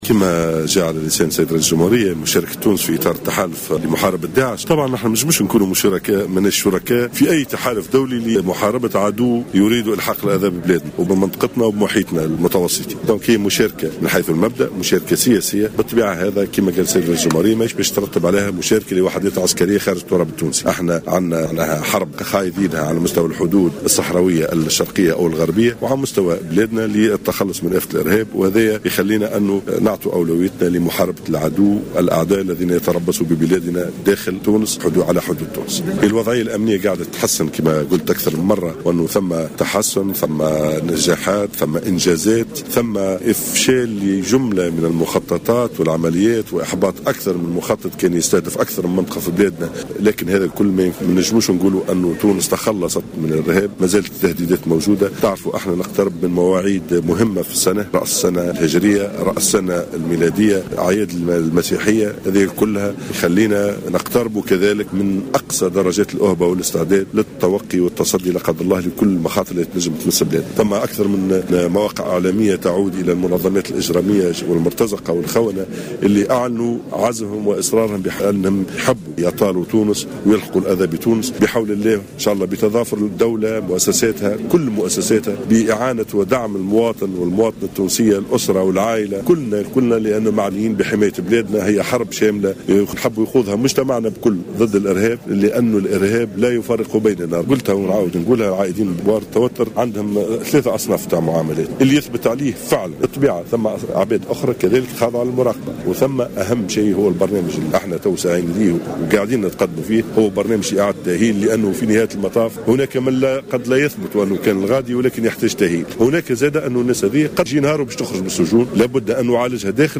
وأضاف الغرسلي اليوم الخميس،خلال إشرافه على تخرج الدفعة 68 لعرفاء الحرس الوطني ببئر بورقبة أن أولويات تونس محاربة الخطر الإرهابي في الداخل وعلى الحدود.